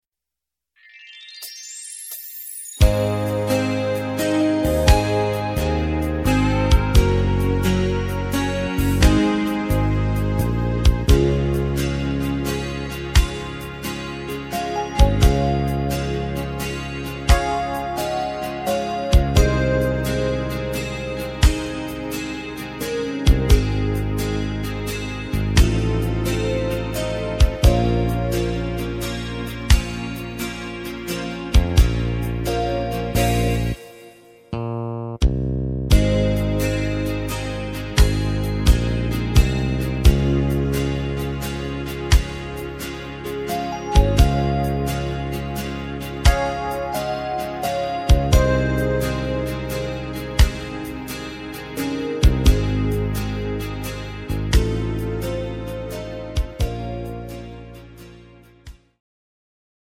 Slow Waltz Version